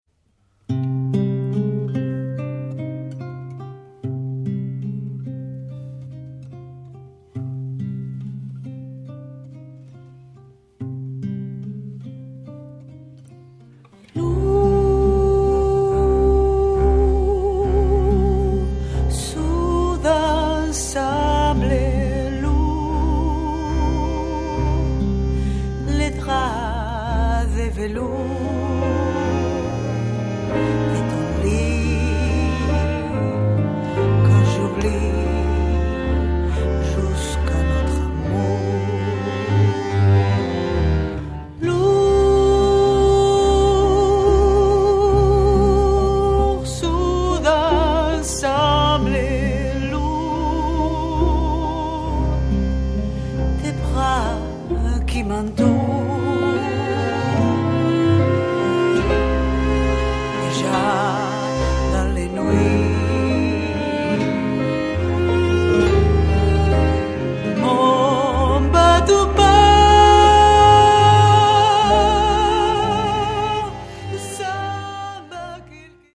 fonctionne actuellement comme un quintette de jazz
Guitare/chant/arrangement
Piano
Bandonéon
Violon alto
Contrebasse